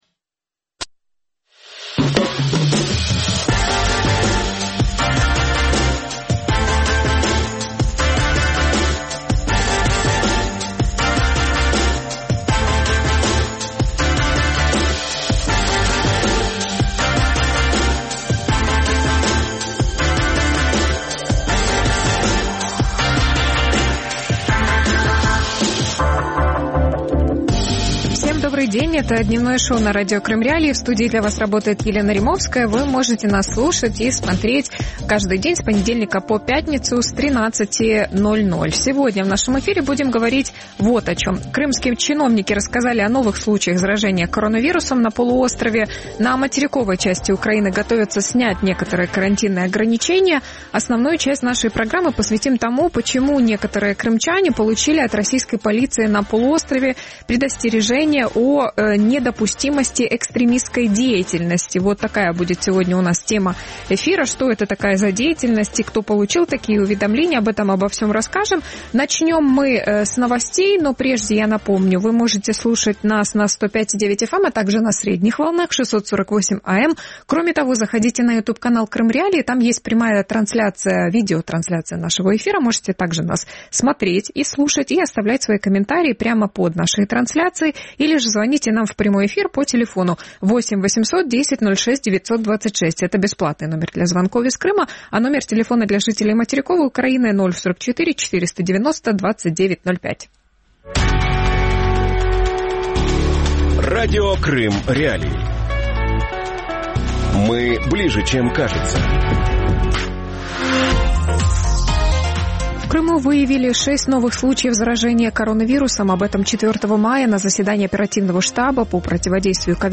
В Крыму снова боятся «экстремистов» | Дневное ток-шоу